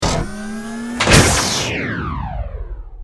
battlesuit_largelaser.ogg